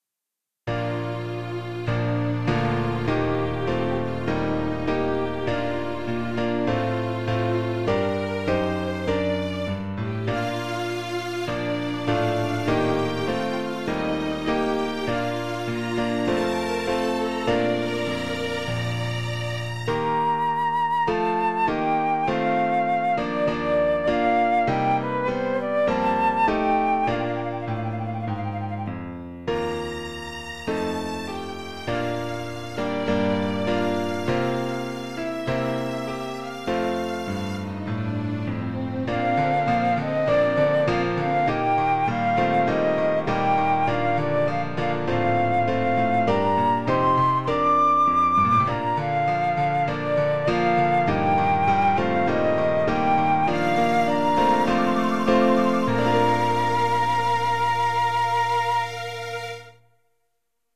국가 음성 클립